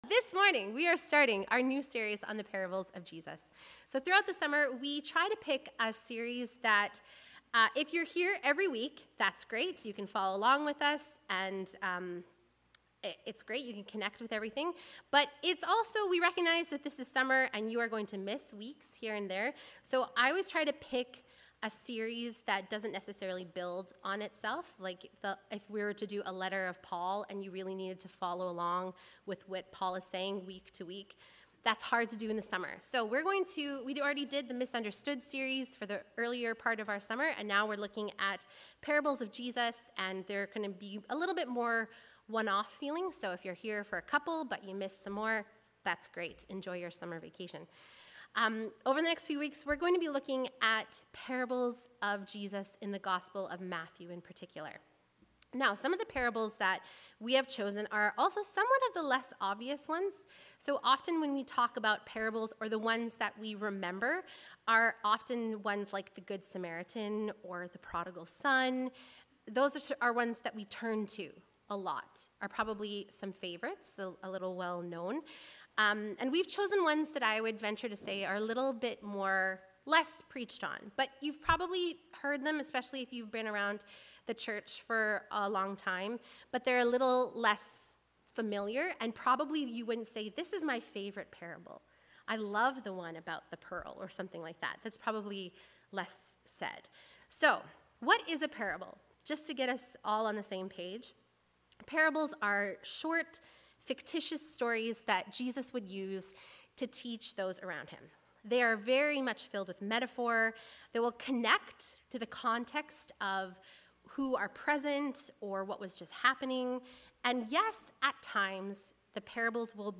Sermons | Olivet Baptist Church